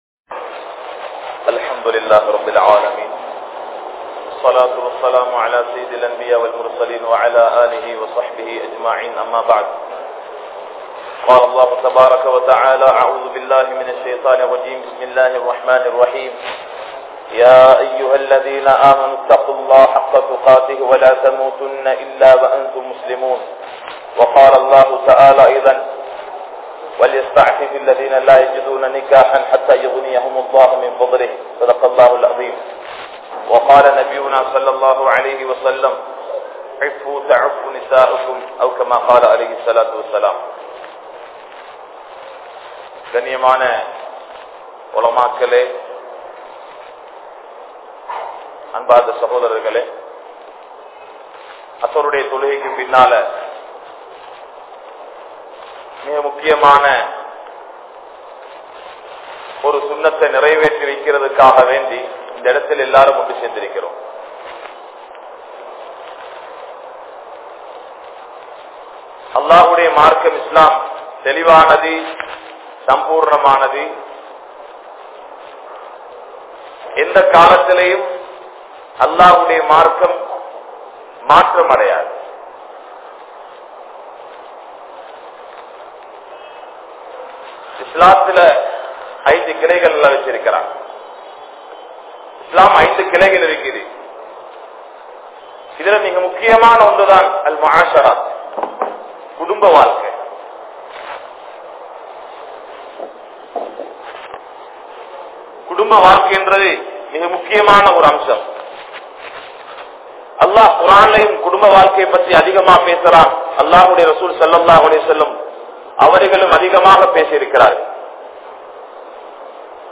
Paththinith Thanam (பத்தினித்தனம்) | Audio Bayans | All Ceylon Muslim Youth Community | Addalaichenai